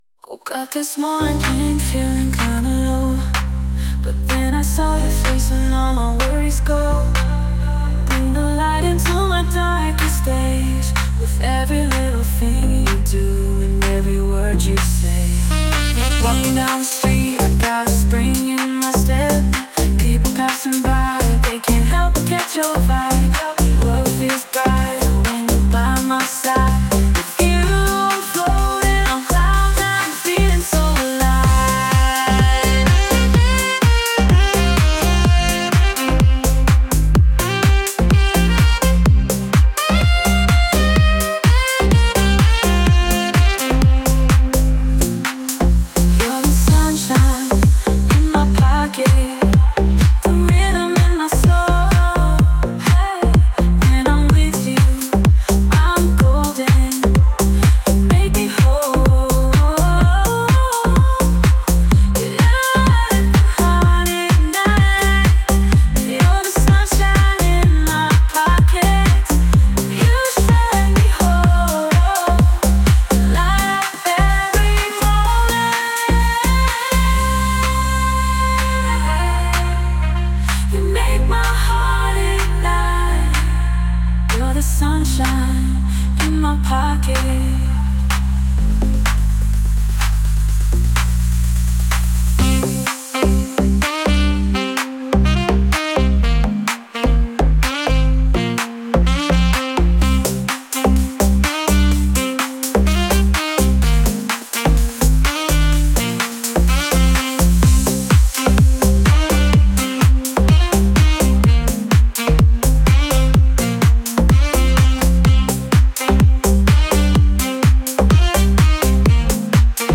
DJ, MIX, DeepHouse, Bass House, strong sad melodic male voice. SAX